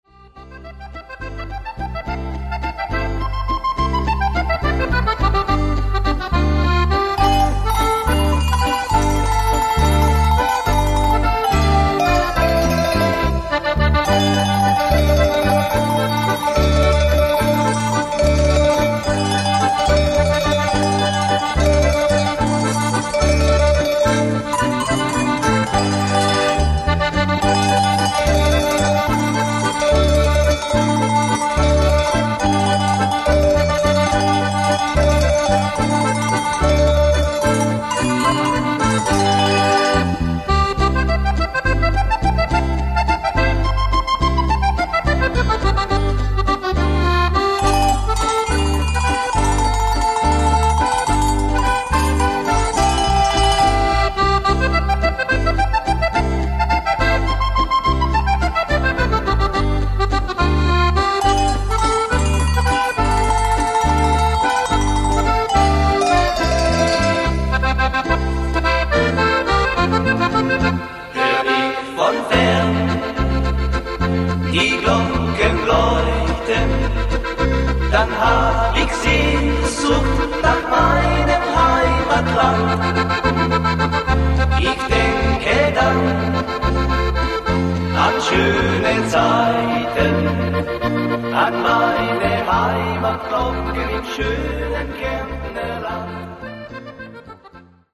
Walzer